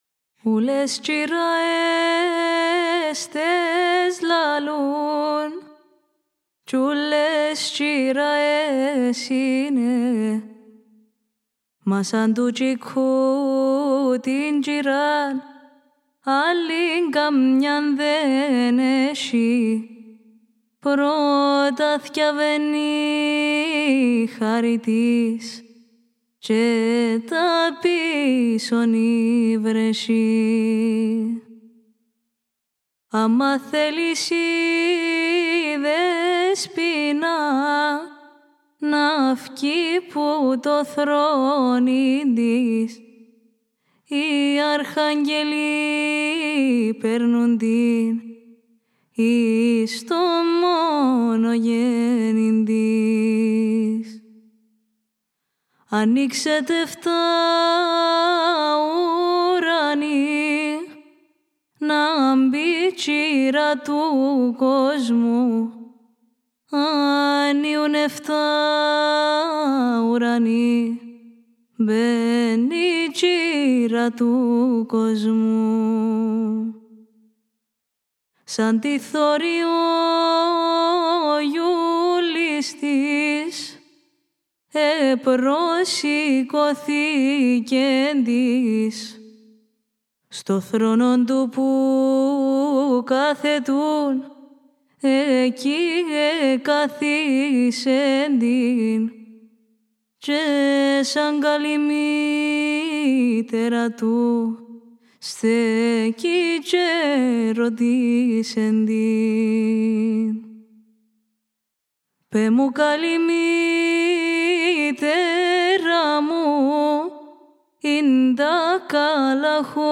Η κυπριακή παραδοσιακή μουσική